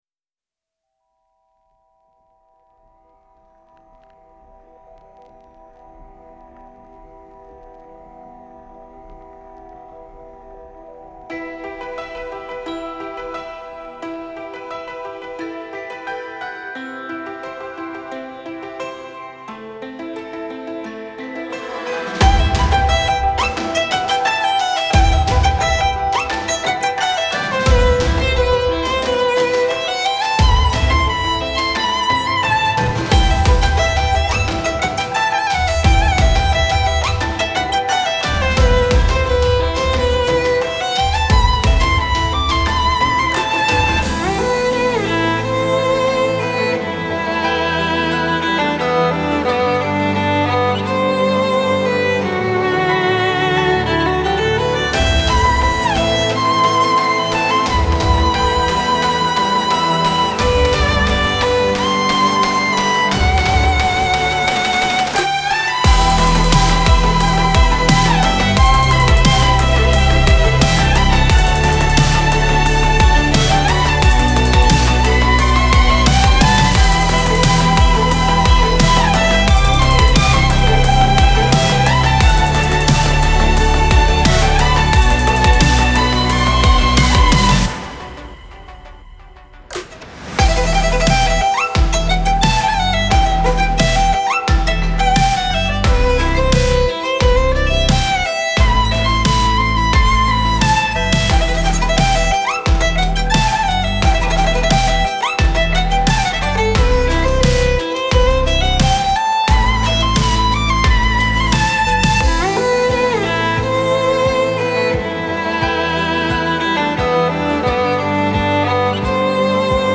Genre : Classical, Electronic